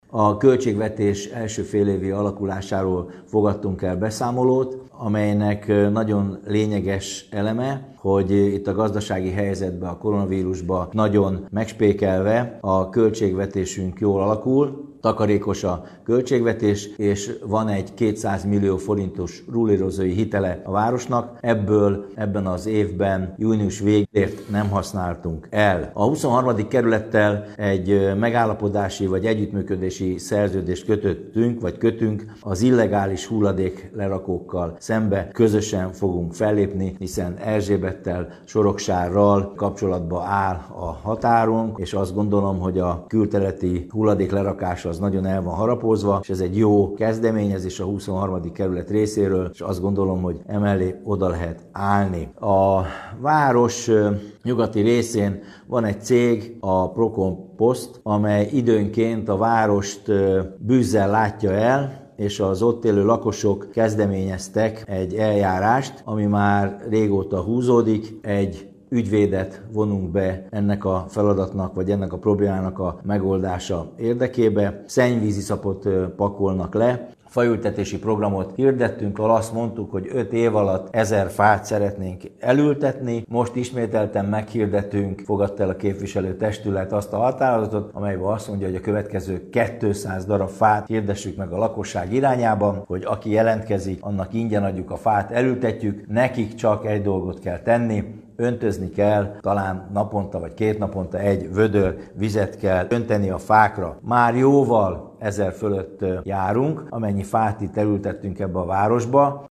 Ennek egyik oka a sári ipari parkban történő beruházás, a másik pedig a Vitafort Zrt. nagy volumenű beruházása. Ez volt a témája a múlt heti közmeghallgatásnak is, amelynek részleteiről Kőszegi Zoltán polgármestert hallják.